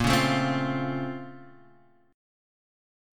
BbmM9 chord